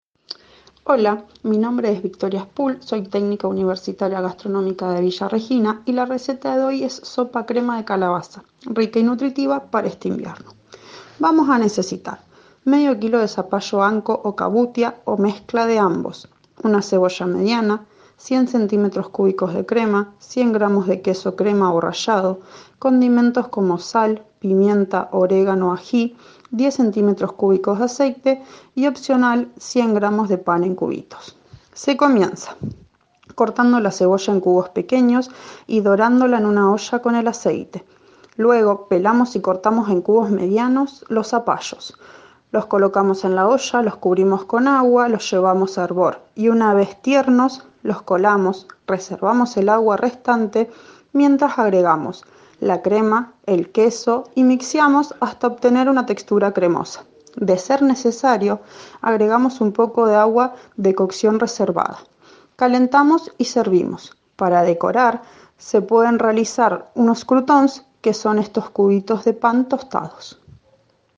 Audio receta de sopa crema de calabaza